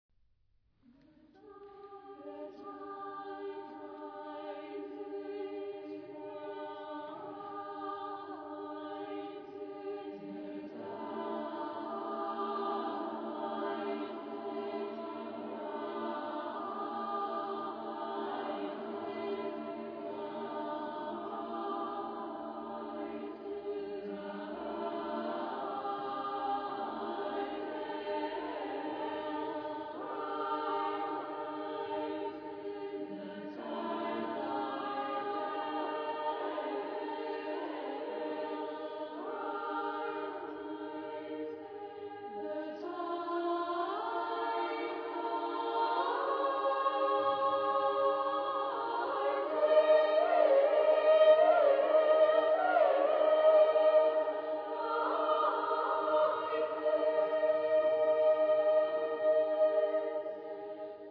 Genre-Style-Form: Secular
Mood of the piece: contemplative
Type of Choir: SSAA  (4 women voices )